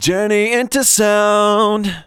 Index of /90_sSampleCDs/Techno_Trance_Essentials/VOCALS/SUNG/C#-BAM